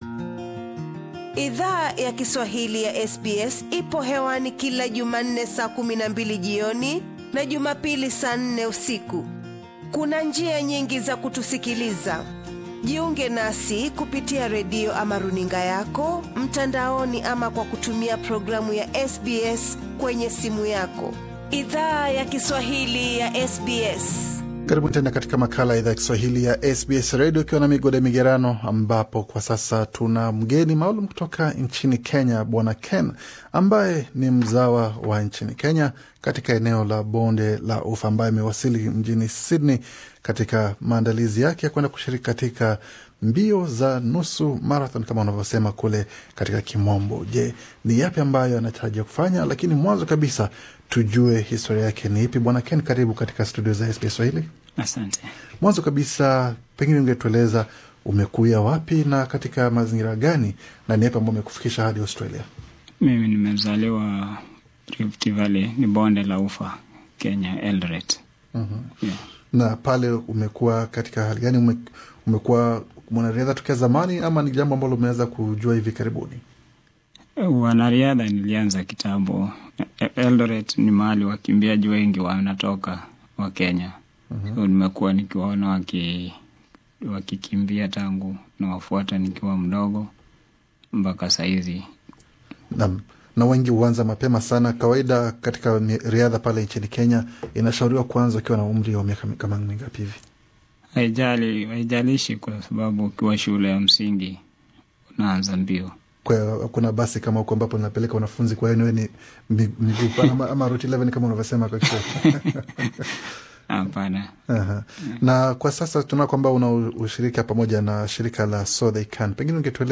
ndani ya studio za SBS
mahojiano